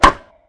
bowling_lob01.mp3